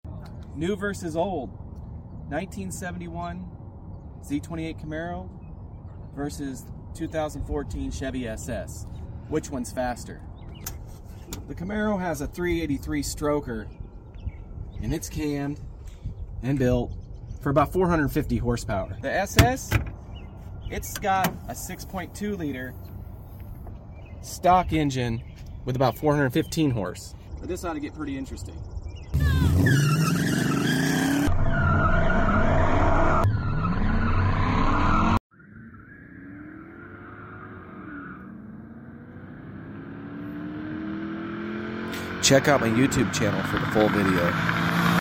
71 camaro vs holden commodore sound effects free download